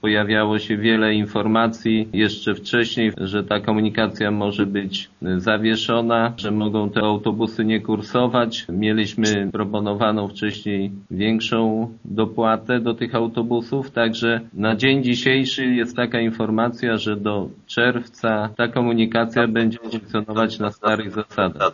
Oznacza to, że przez najbliższe pół roku autobusy lubelskiego MPK będą obsługiwać linie na terenie gminy Głusk tak jak do tej pory – informuje wójt Jacek Anasiewicz: